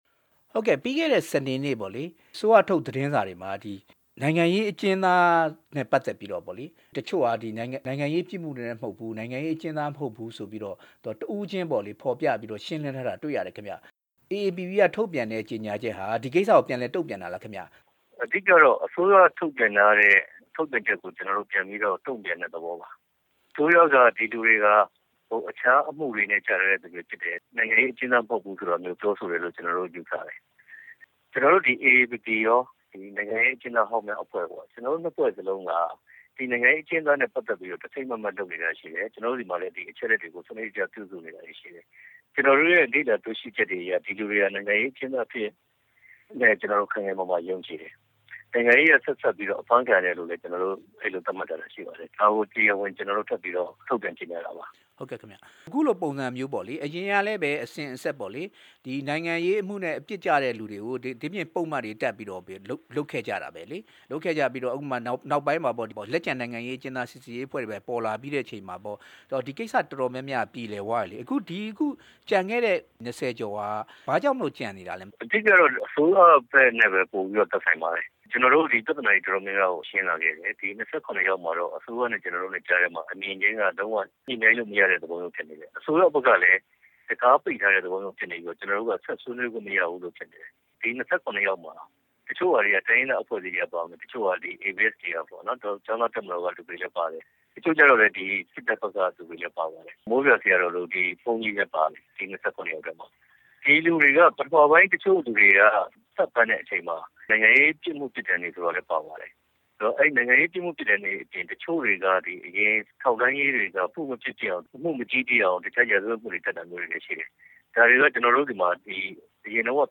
နိုင်ငံရေး အကျဉ်းသား ၂၇ ဦး အရေးယူထားတဲ့ အကြောင်း မေးမြန်းချက်